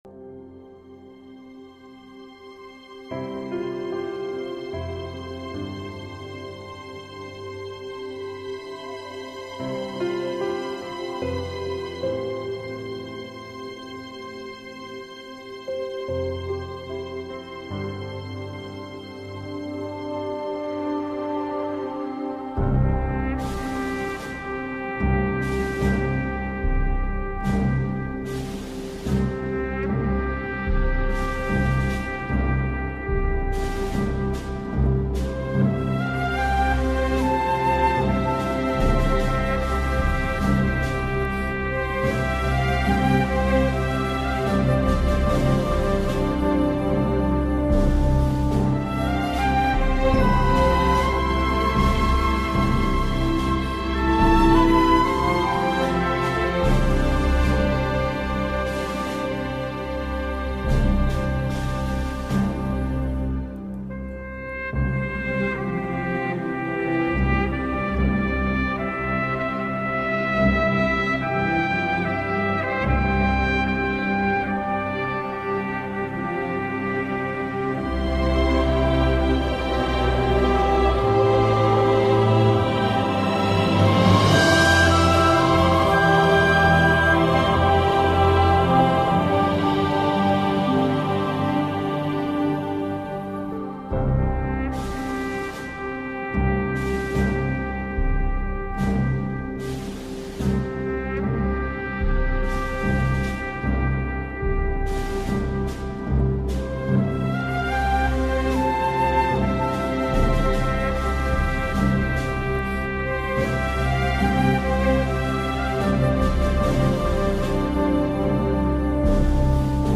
Cornerstone Fellowship Sunday morning online service, May 24, 2020. It’s Memorial Day weekend and on the church calendar it is also the 10 days between the Ascension of Christ and the outpouring of the Holy Spirit on the Day of Pentecost!